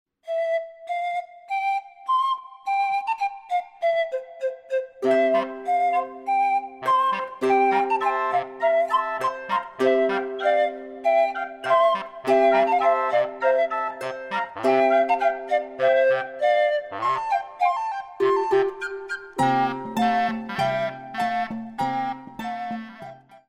古楽器たちが踊り出す。
心地よい古楽器のアンサンブルをお楽しみ下さい。